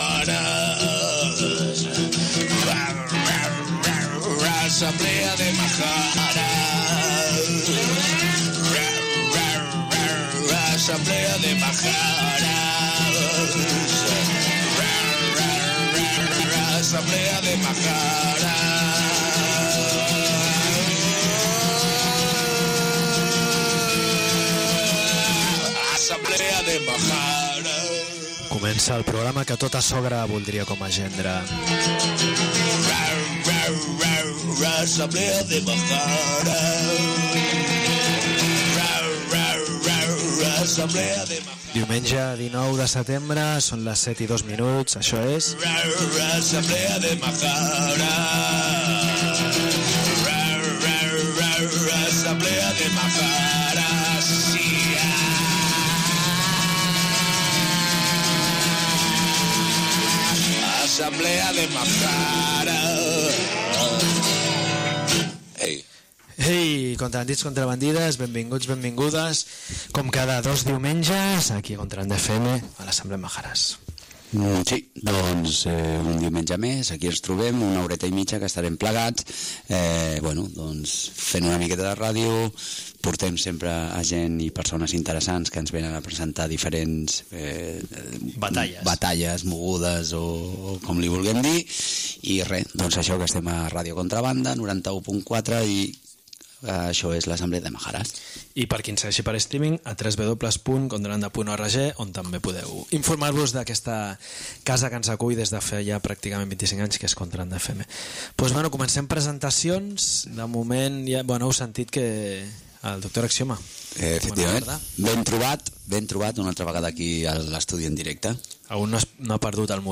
Música de recuperació que desempolvem: